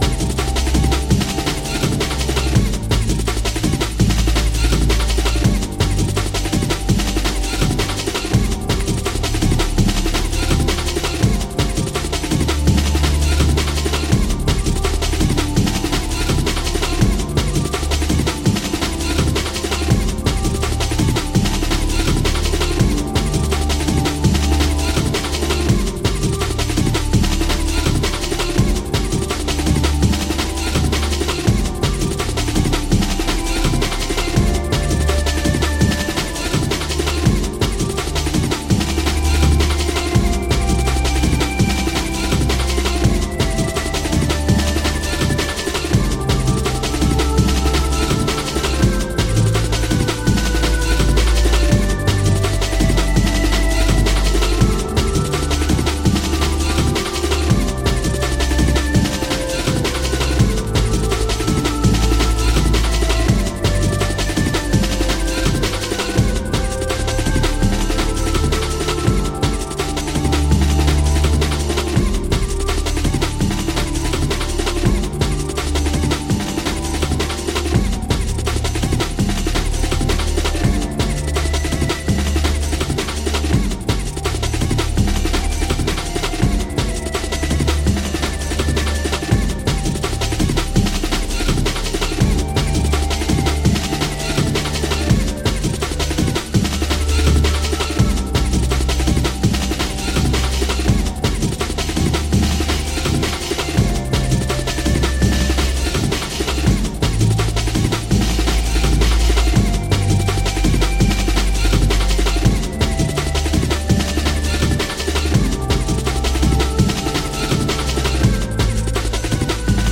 brooding and twisted braindance